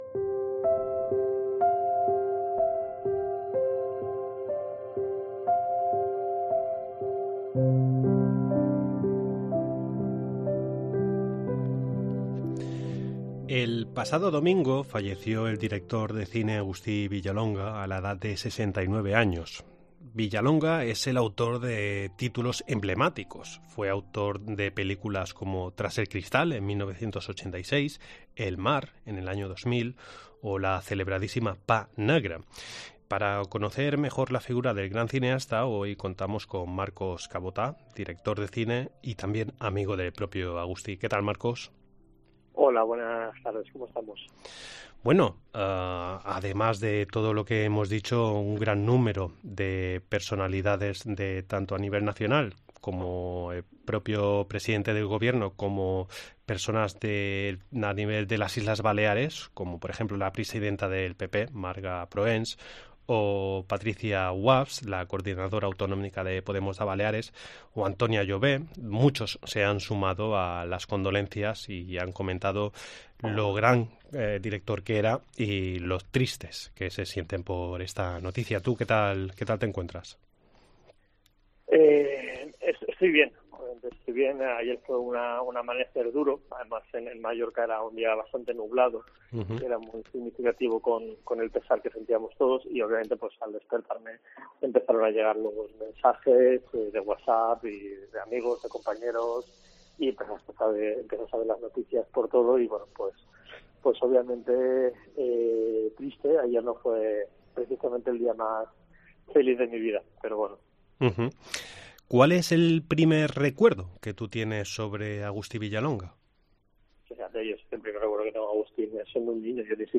Desde COPE Baleares nos sumanos a las condolencias y realizamos un pequeño homenaje en forma de entrevista a la figura del gran cineasta.